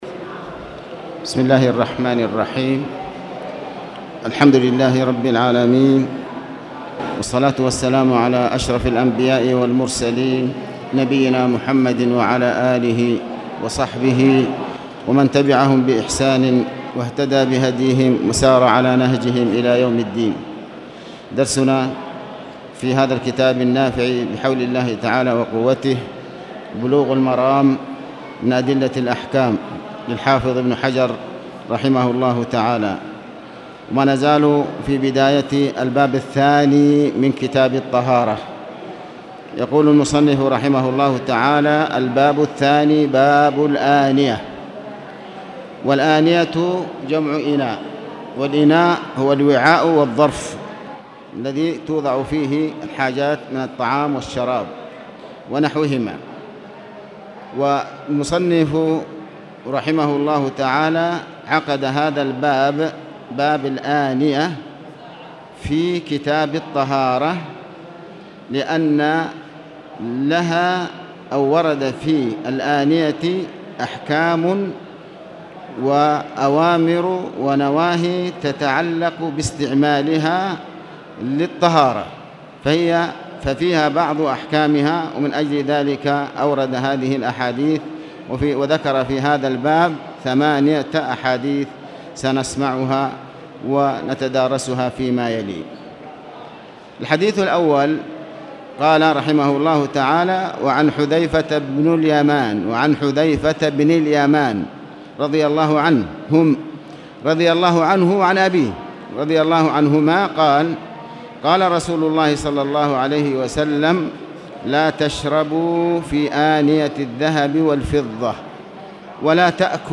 تاريخ النشر ١٩ محرم ١٤٣٨ هـ المكان: المسجد الحرام الشيخ